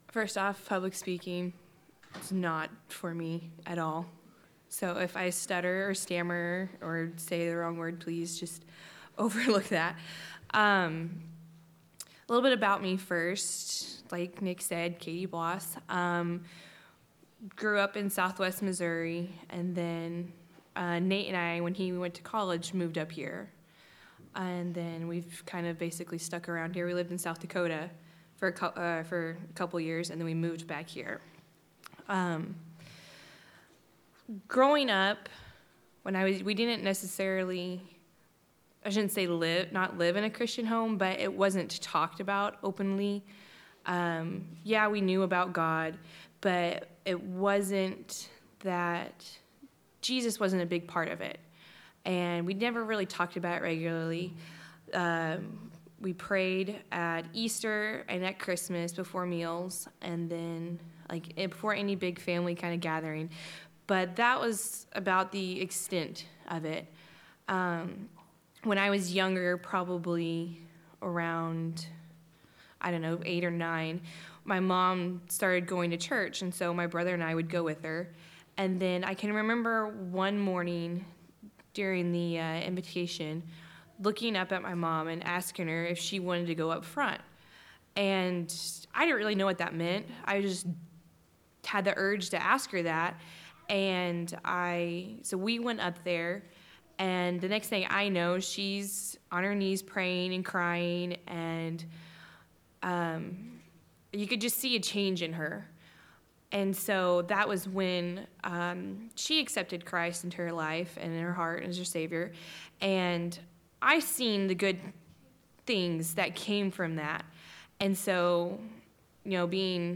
Testimonies of Faith
A couple weeks ago, we had our annual New Life Celebration. This is an event we do each year to celebrate the salvation of those who came to faith in the past year, and pray for more response to the gospel in the coming year.